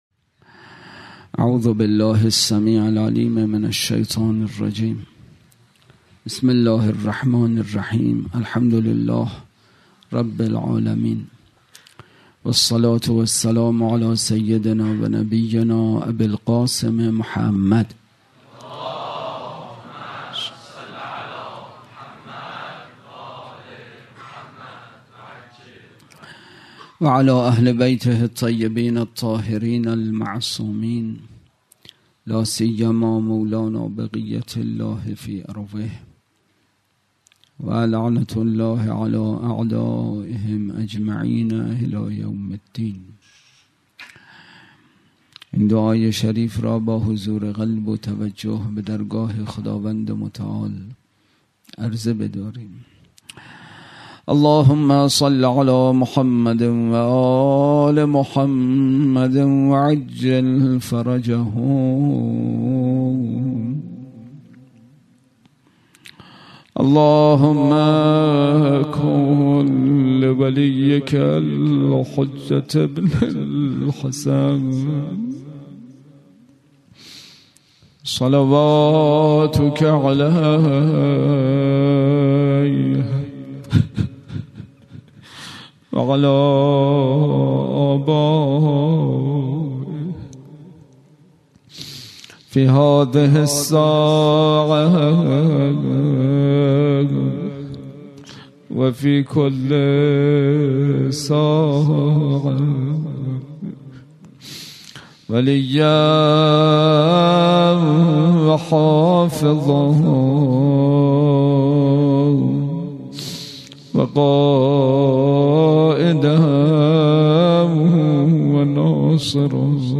اطلاعات آلبوم سخنرانی
برگزارکننده: دبیرستان علوی